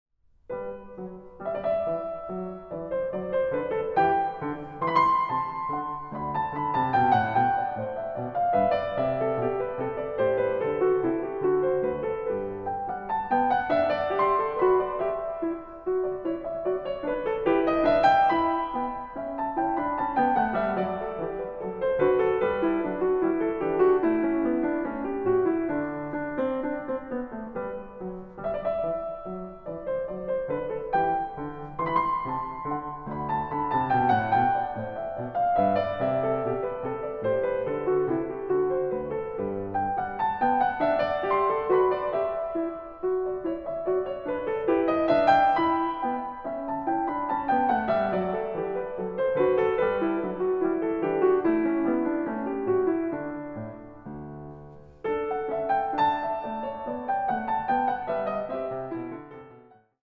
Piano
Recording: Jesus-Christus-Kirche Berlin-Dahlem, 2024